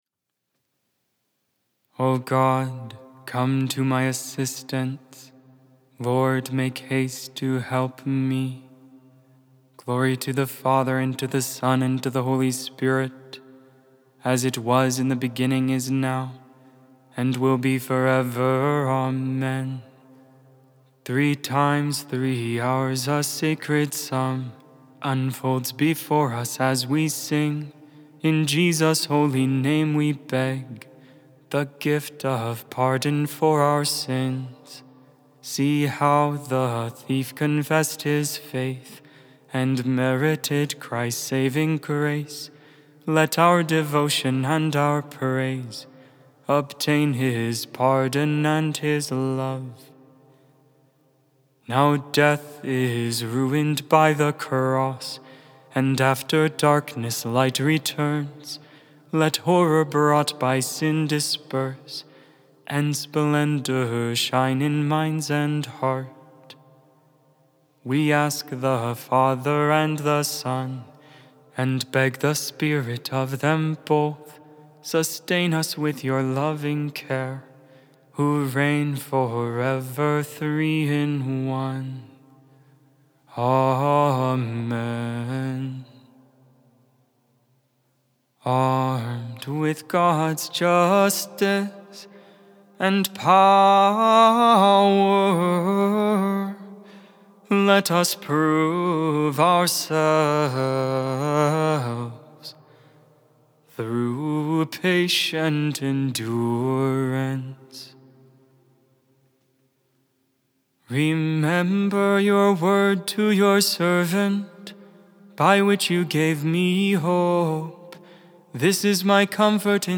Music, Christianity, Religion & Spirituality